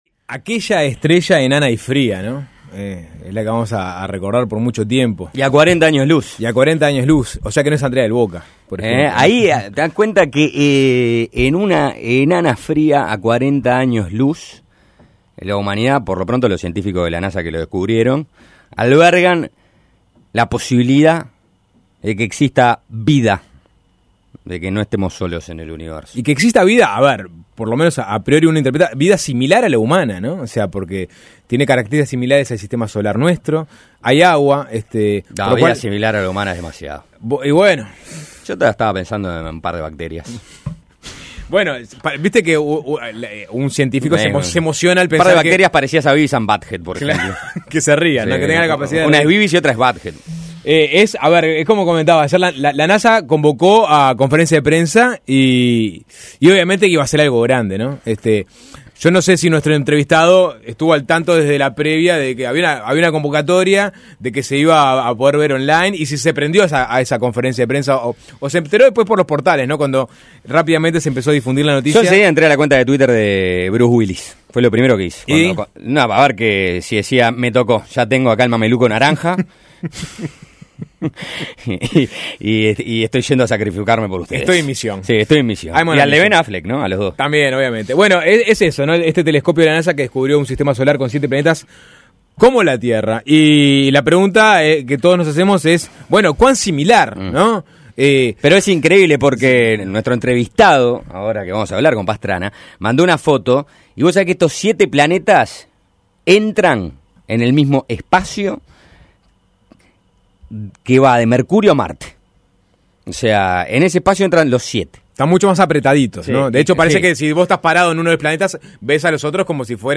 Entrevista en Suena Tremendo ¿Estamos más cerca de saber si hay vida en el espacio exterior?